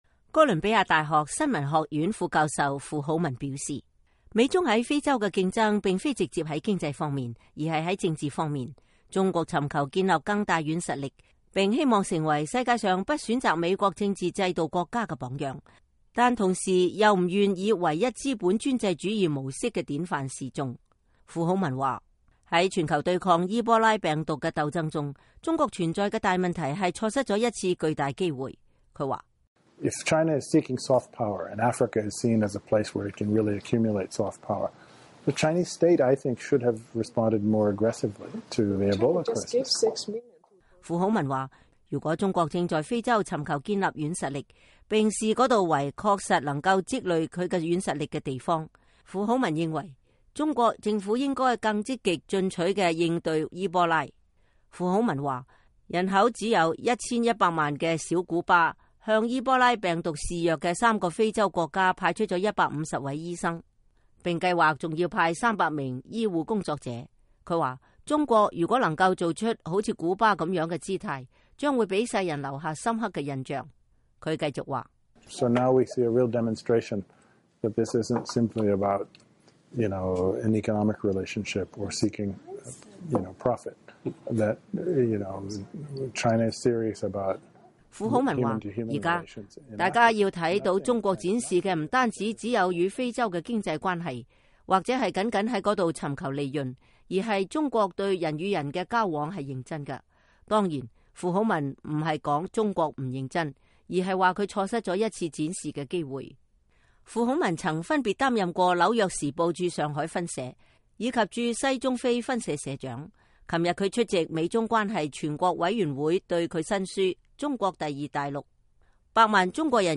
《百萬中國人移民非洲》一書作者、哥倫比亞大學新聞學院副教授傅好文，在紐約接受美國之音採訪時表示，在全球對抗伊波拉病毒蔓延的鬥爭中，中國錯失了一次建立軟實力的良機。